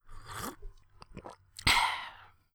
drink-sip-and-swallow.wav